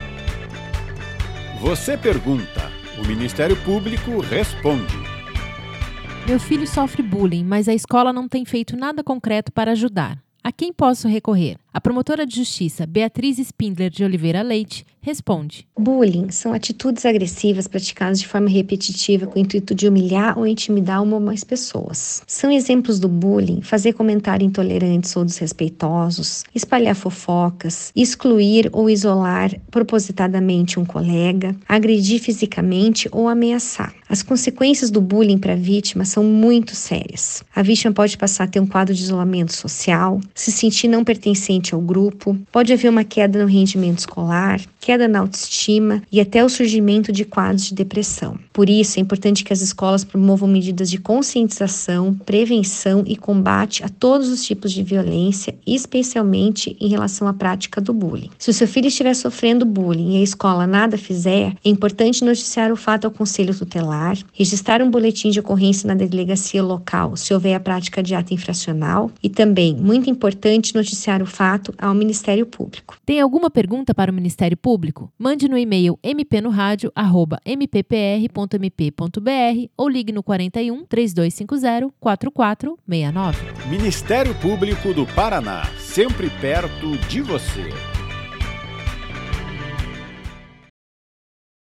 Você pergunta, o MP responde. Em áudios curtos, de aproximadamente um minuto, procuradores e promotores de Justiça esclarecem dúvidas da população sobre questões relacionadas às áreas de atuação do Ministério Público.
Os spots do MP Responde são produzidos pela Assessoria de Comunicação do Ministério Público do Paraná.